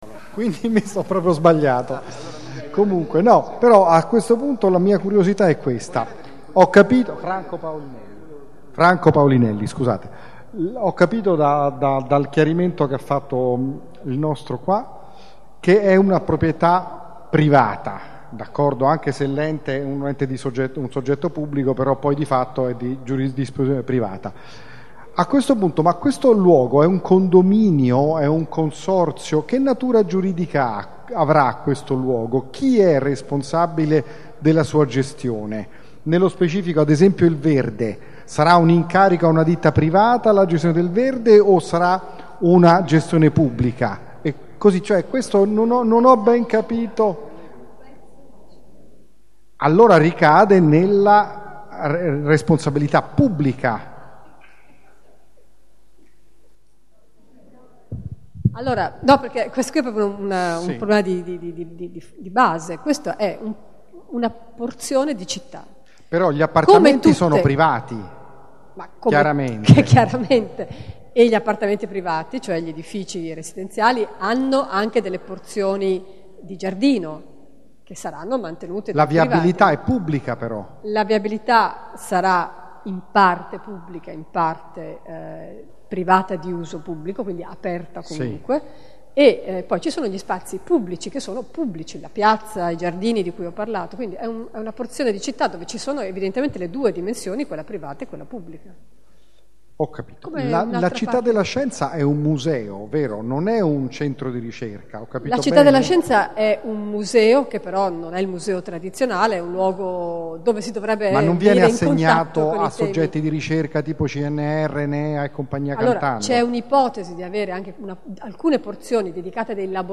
Piano di recupero del Quartiere Città della Scienza - Ascolto audio del secondo incontro partecipativo